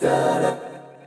Presidential Vox 1.wav